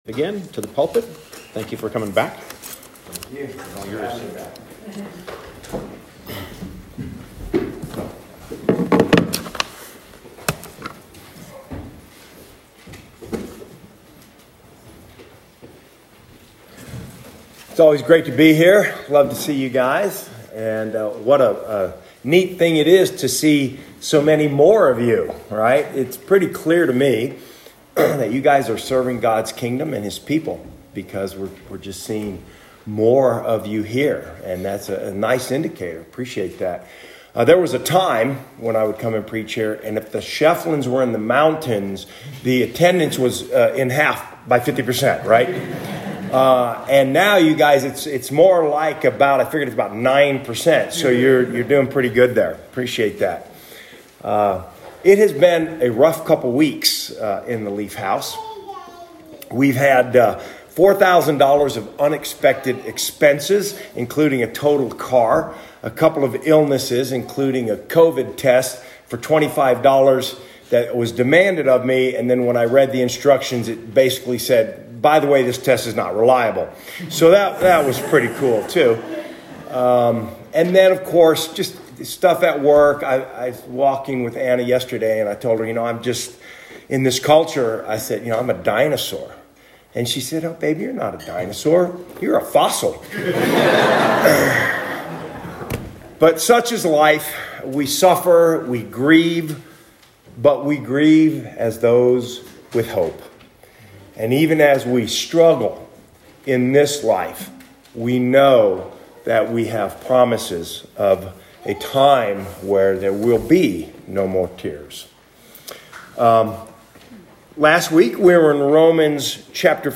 Romans 6:1-14 Service Type: Morning Service Believers in Christ have died to sin and been raised to new life.